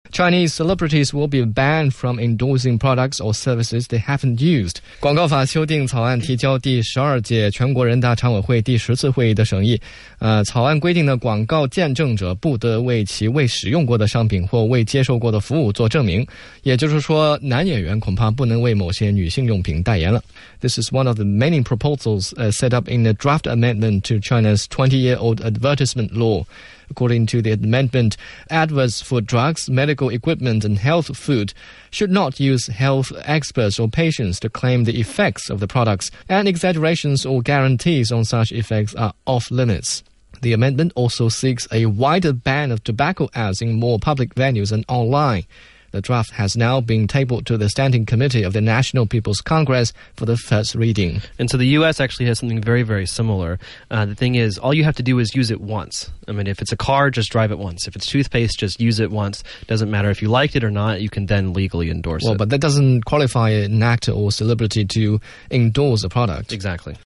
中英双语的音频，能够帮助提高英语学习者的英语听说水平，中外主持人的地道发音，是可供模仿的最好的英语学习材料,可以帮助英语学习者在轻松娱乐的氛围中逐渐提高英语学习水平。